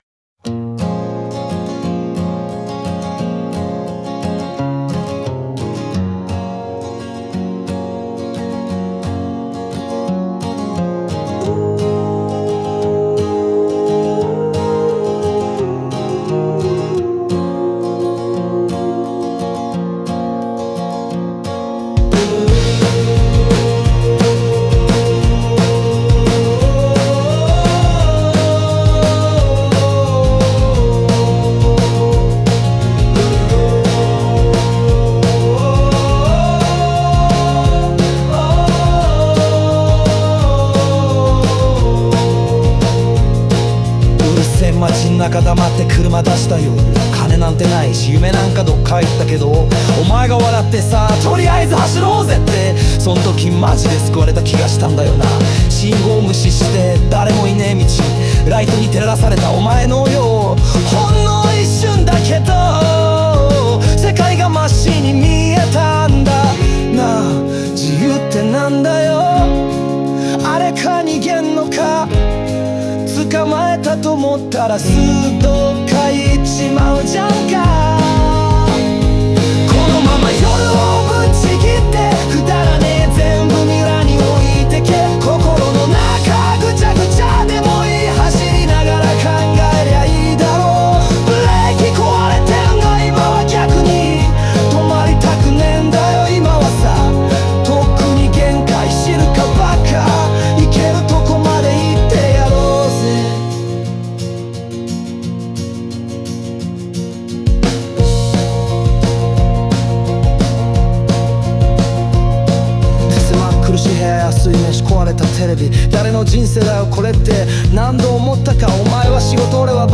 荒れた口調と感情むき出しの語り口が、現実の重さと心の葛藤をリアルに浮き彫りにする。
静かな語りから感情が爆発するサビへと展開し、心の揺れや葛藤がリスナーの胸を打つ。